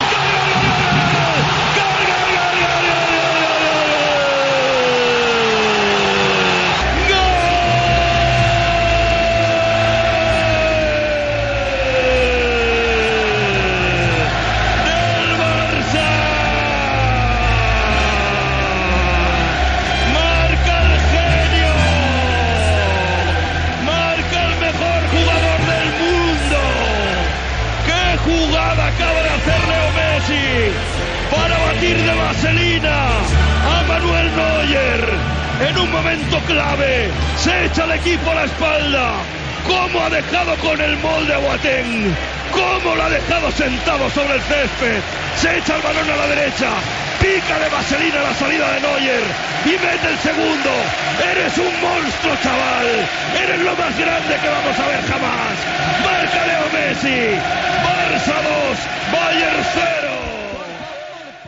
Transmissió del partit de la fase eliminatòria de la Copa d'Europa de futbol masculí entre el Futbol Club Barcelona i el Bayern München.
Minut 34 de la segona part. Narració del segon gol de Leo Messi.
Esportiu